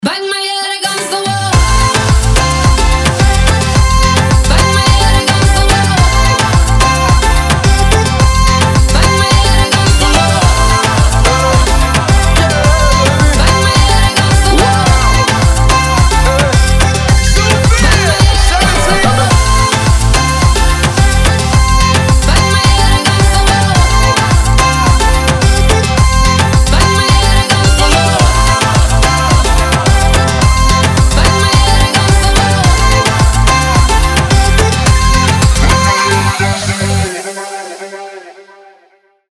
• Качество: 320, Stereo
Хип-хоп
dance